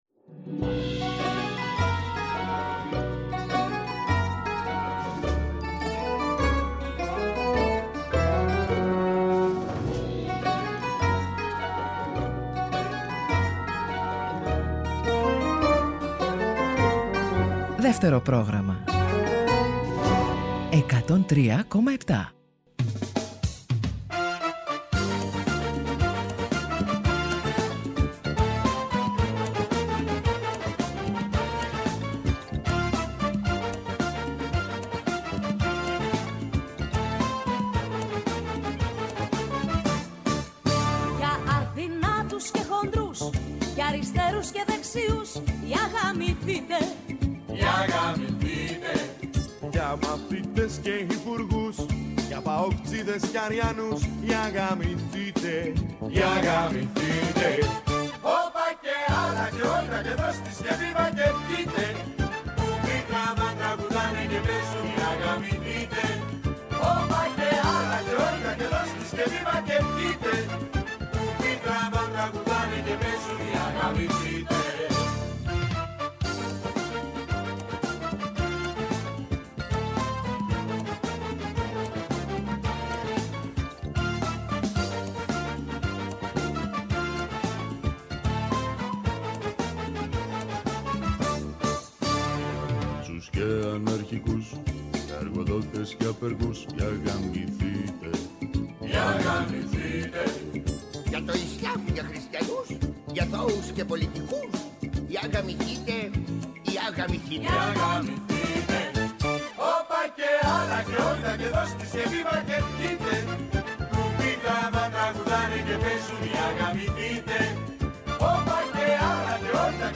Έτσι λοιπόν, Τρίτη και 13, οι Ροκ Συναναστροφές προσπερνούν τις ..προλήψεις και φιλοξενούν με μεγάλη χαρά στο στούντιο τον Δημήτρη Σταρόβα, με την ευκαιρία της επιστροφής του στη μουσική με πλήθος νέων εμφανίσεων, παρουσιάζοντας παράλληλα το soundtrack της ζωής του.
Συνεντεύξεις